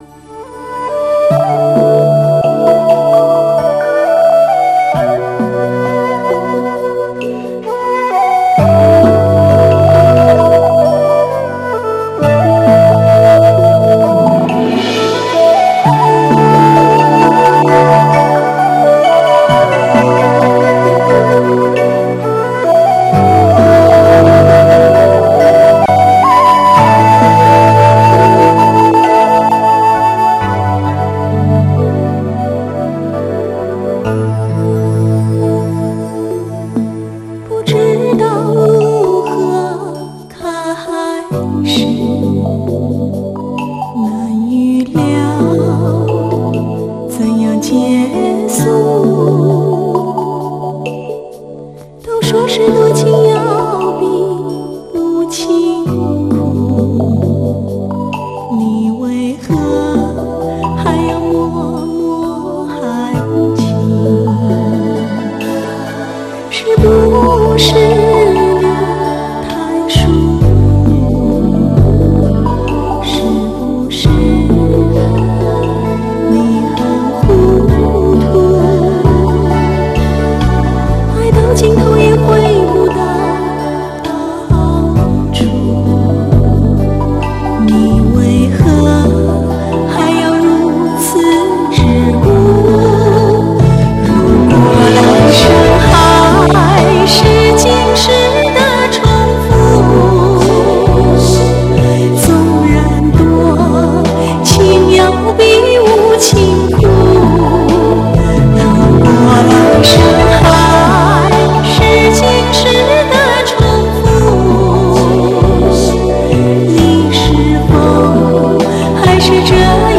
[10/12/2008]我们来讨论一下“古典味道浓、略微带些伤情、词美曲美意境美”的华语歌曲罢